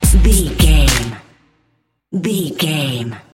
Aeolian/Minor
drum machine
synthesiser
drums
hip hop
soul
Funk
energetic
bouncy
funky